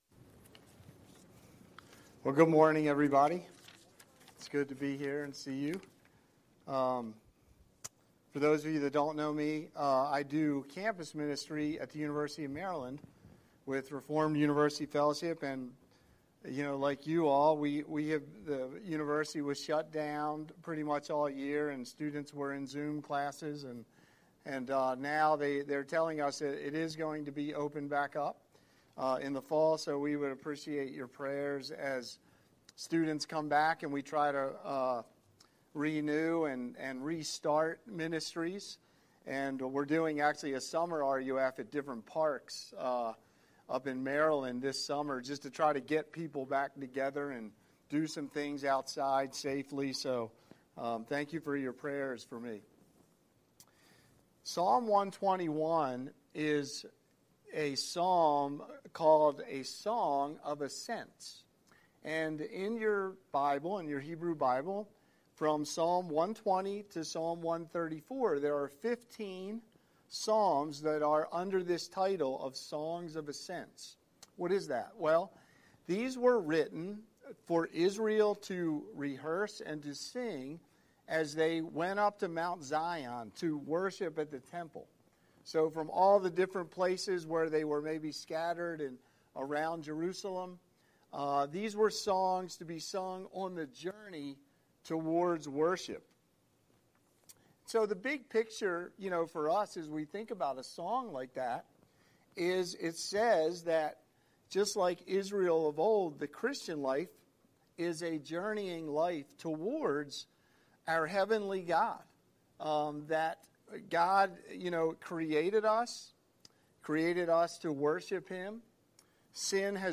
Scripture: Psalm 121:1-8 Series: Sunday Sermon